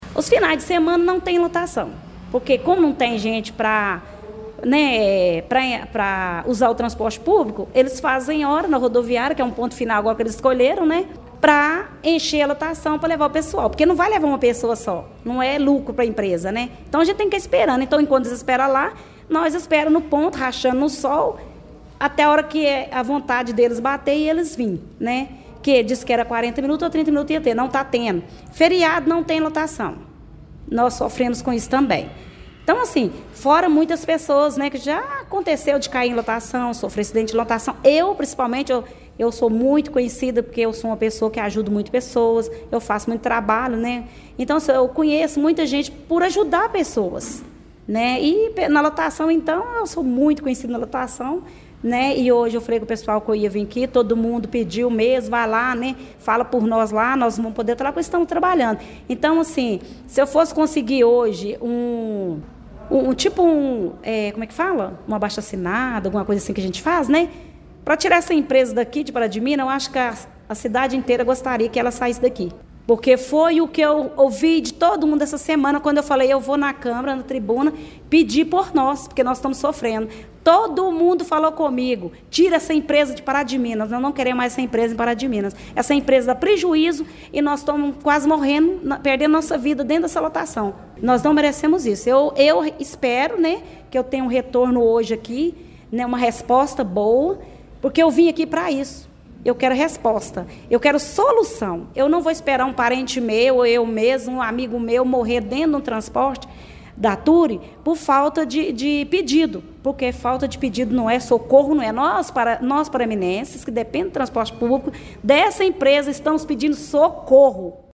A 7ª Reunião Ordinária da Câmara Municipal de Pará de Minas, realizada nesta terça-feira (03), foi marcada por fortes críticas ao transporte coletivo urbano.